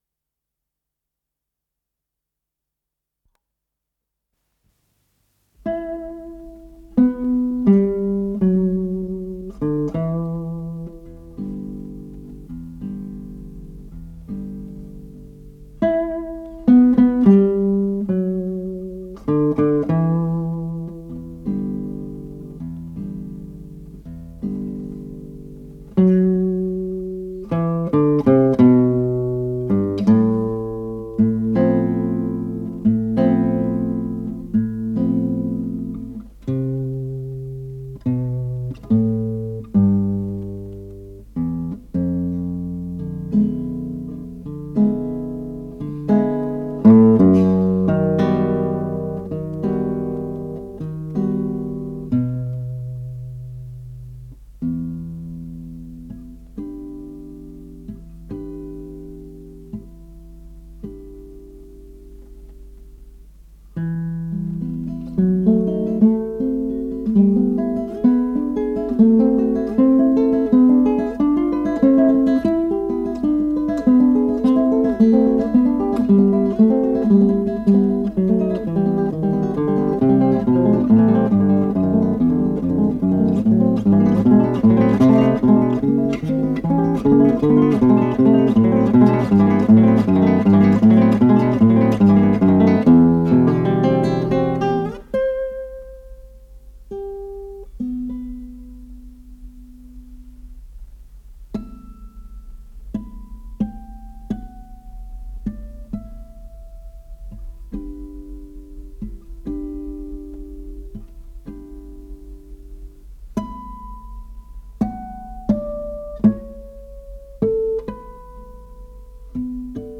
с профессиональной магнитной ленты
гитара
ВариантДубль моно